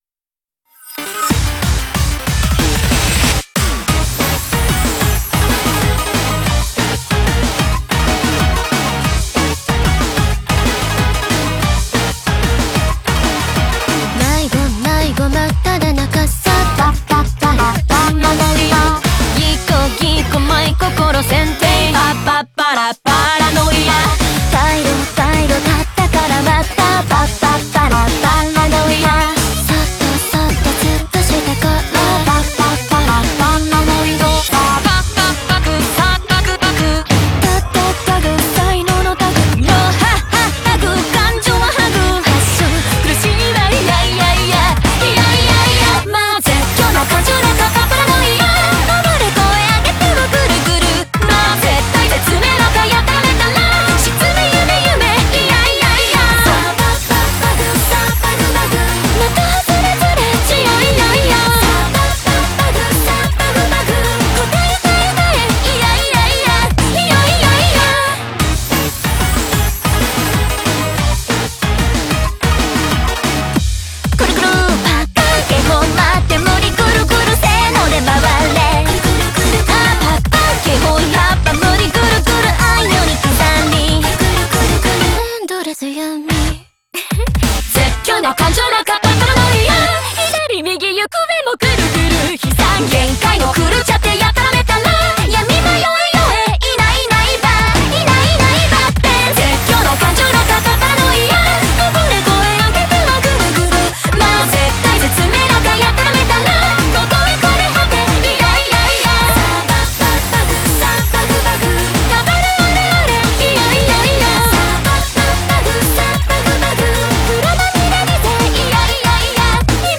BPM186
Audio QualityMusic Cut